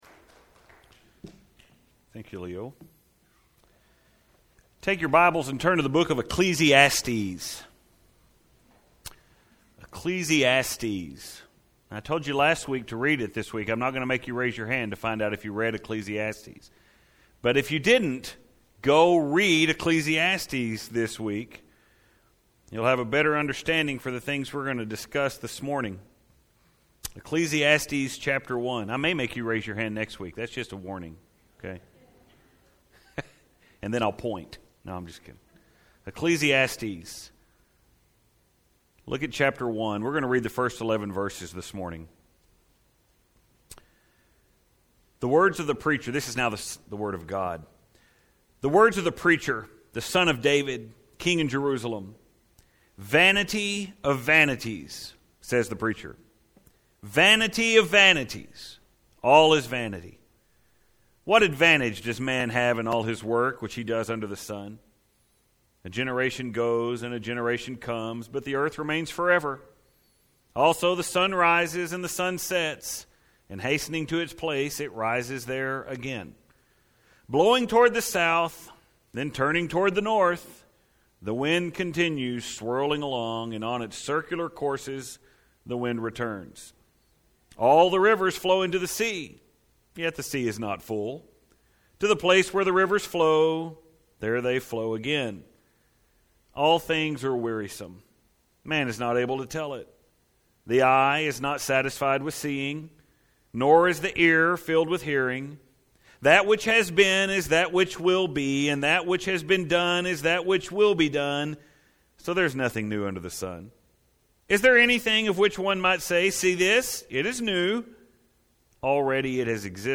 I’ve certainly never been one to want to include movie clips in a sermon, but if I was ever going to do it, I would have started this one with that opening clip from the Lion King with the singing of “The Circle of Life”